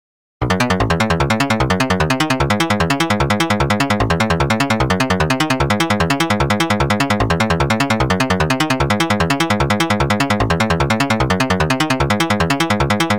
ベースもこの通り(´・ω・｀)